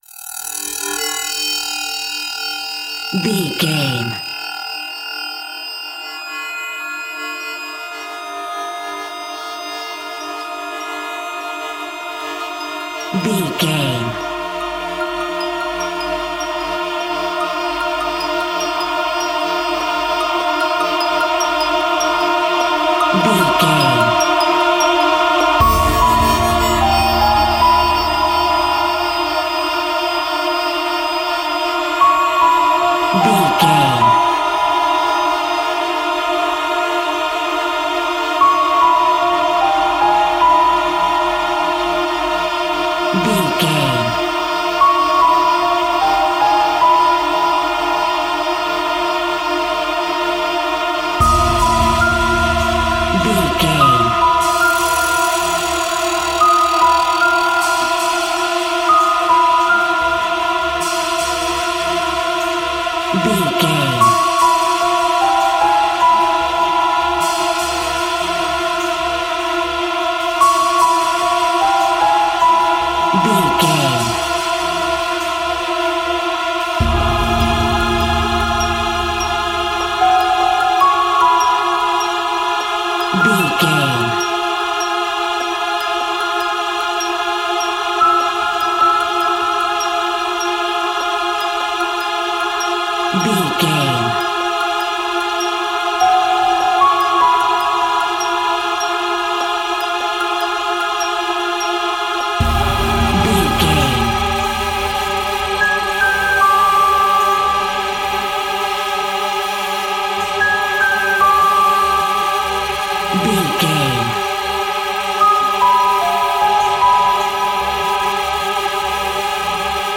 Atonal
Slow
scary
tension
ominous
dark
haunting
eerie
synthesiser
instrumentals
horror music
Horror Pads
horror piano
Horror Synths